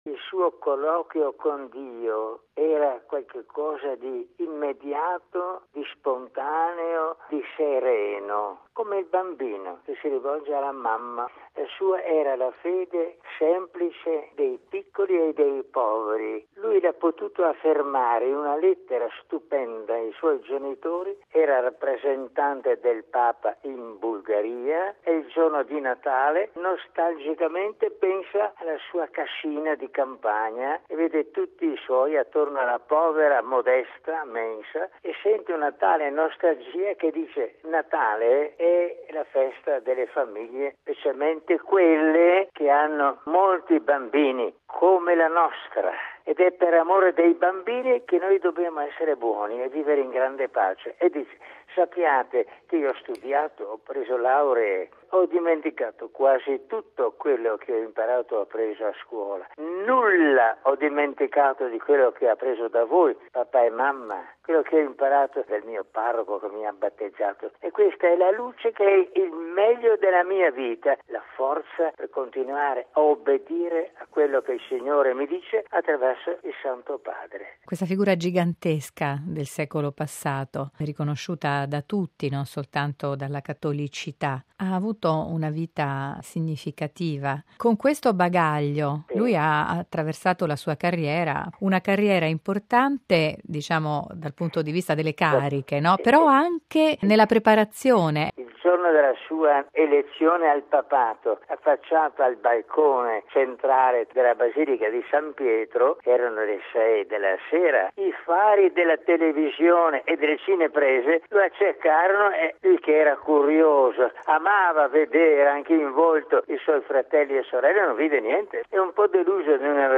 Messa in San Pietro a 50 anni dalla morte di Giovanni XXIII. Intervista con mons. Capovilla